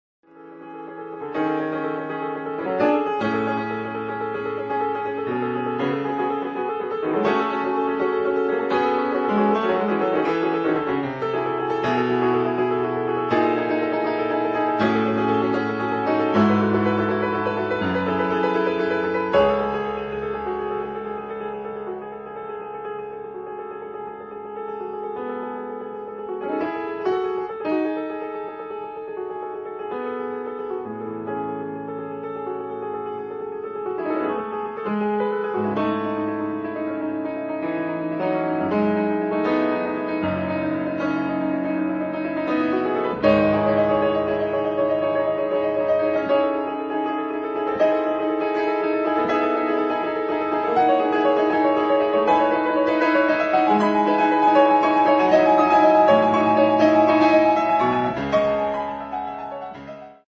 pianoforte "solo"